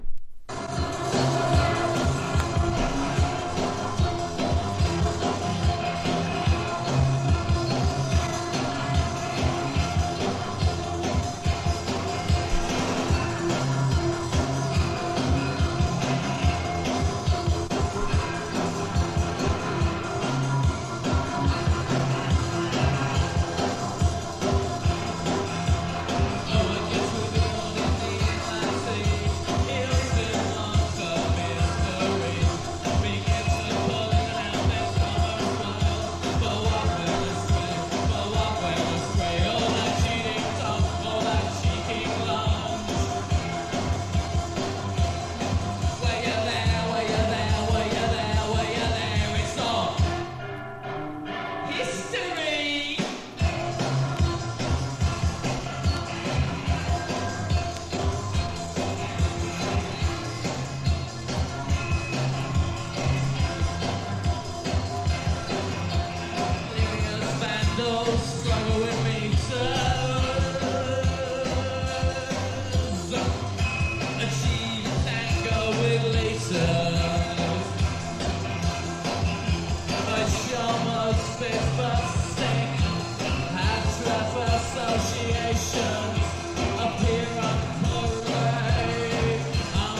85年から86年にアムステルダムで行われたライブ音源を収録した貴重盤。
POST PUNK